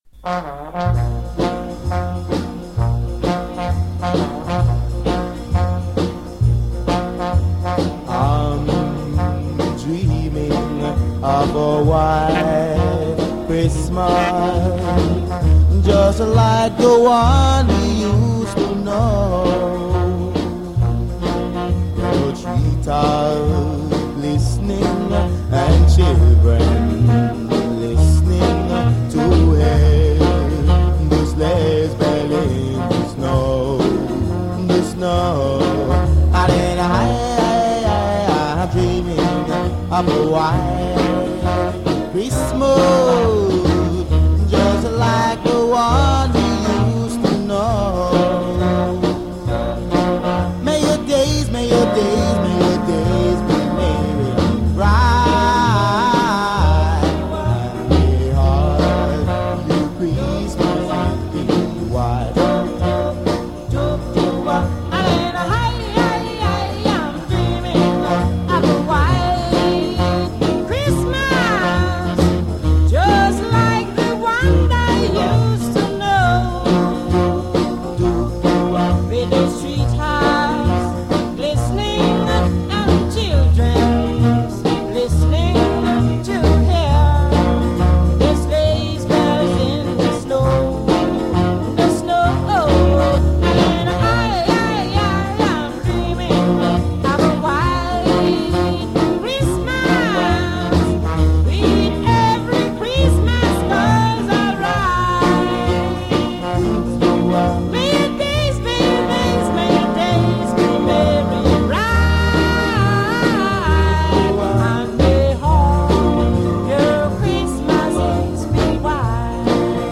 festive ska cut
pioneering Jamaican duo